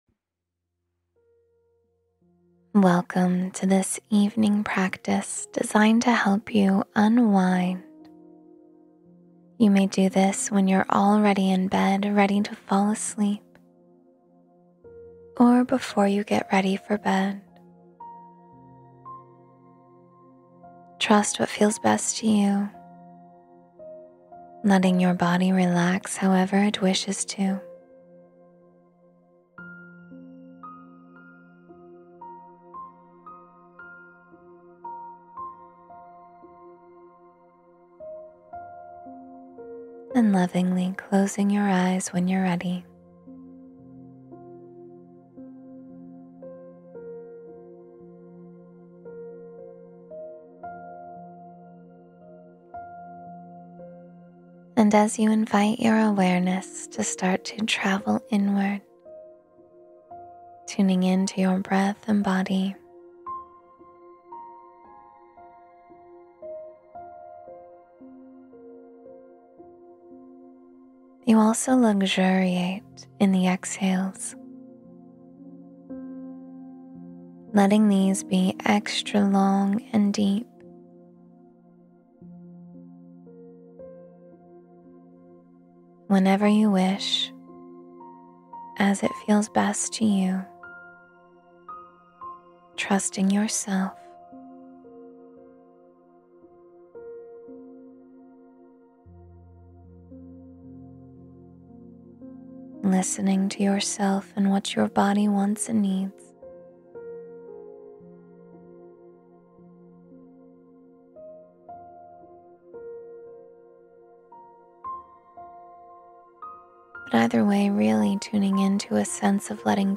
20 Minutes to Unwind Before Sleep — Guided Meditation for Restful Sleep